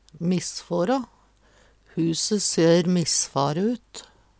DIALEKTORD PÅ NORMERT NORSK missfårå misfare, øydeleggje Infinitiv Presens Preteritum Perfektum missfårå missfær missfor missfare Eksempel på bruk Huse ser missfare ut.